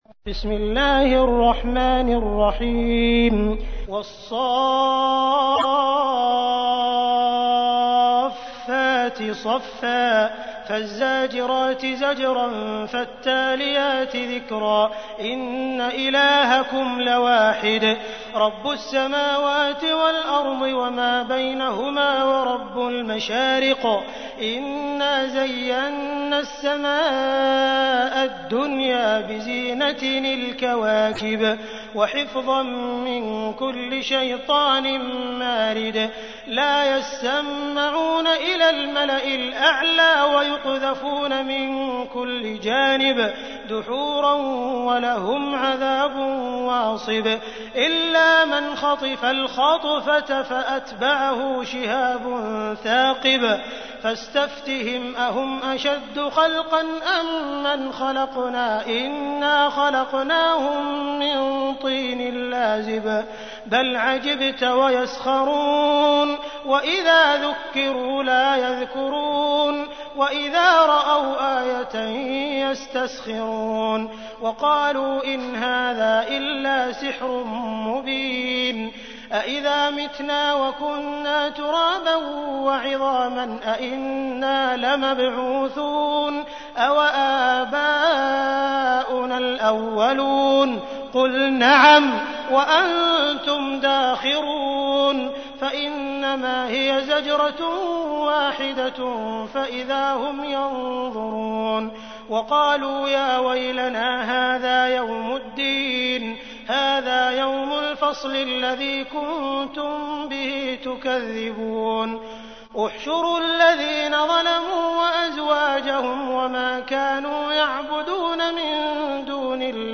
تحميل : 37. سورة الصافات / القارئ عبد الرحمن السديس / القرآن الكريم / موقع يا حسين